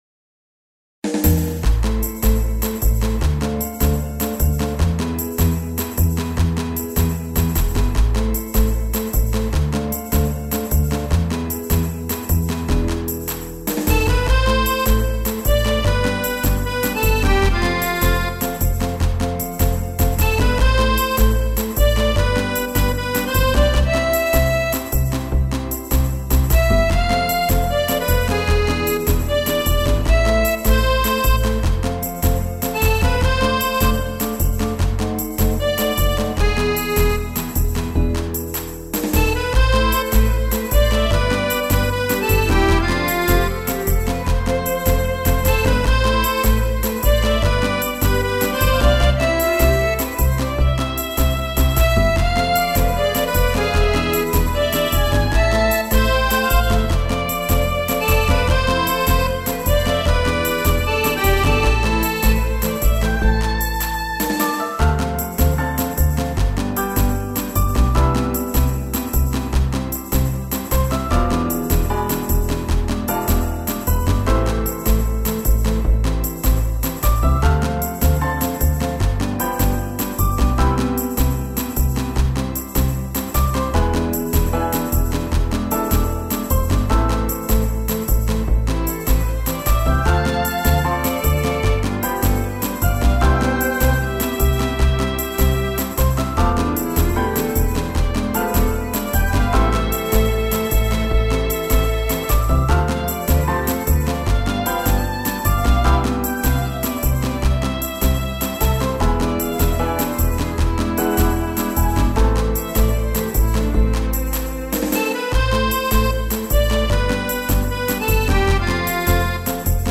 BGM
カントリーロング暗い